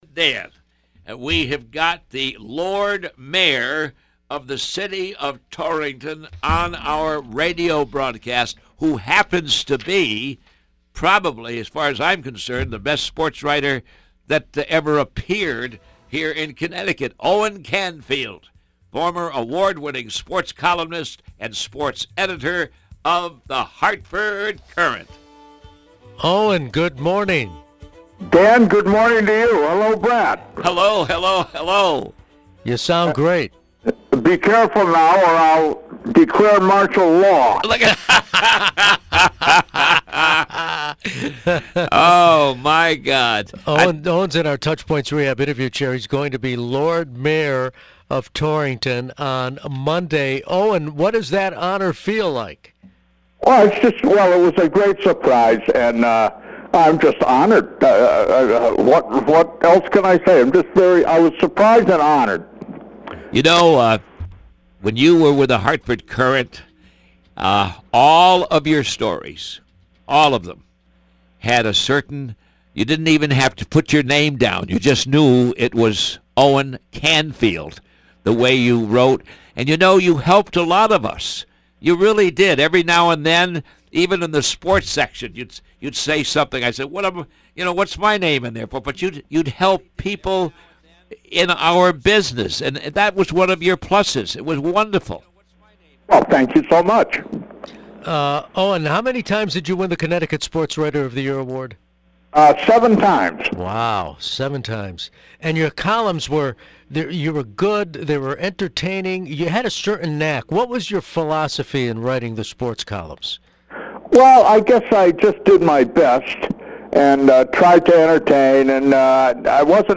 In an interview